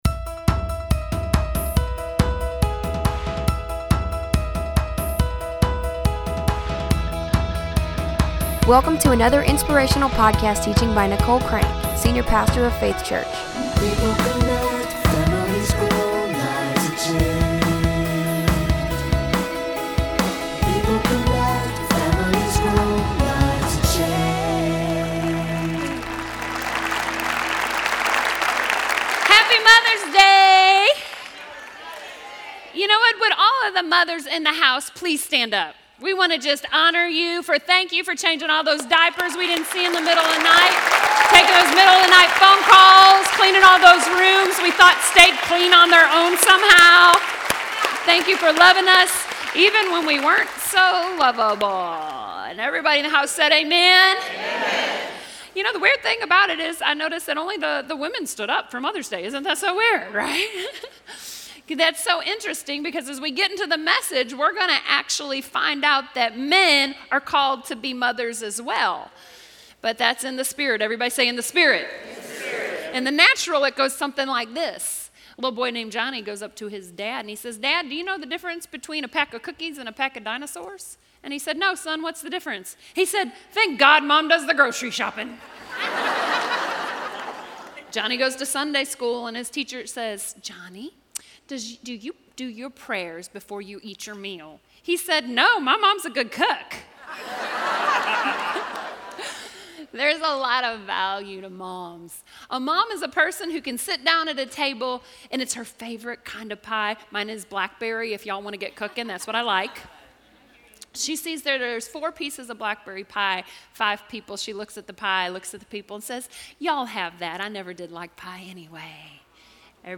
You don't want to miss this passionate message of "giving birth" to the dreams you think are impossible.